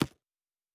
pgs/Assets/Audio/Sci-Fi Sounds/Interface/Click 10.wav at master
Click 10.wav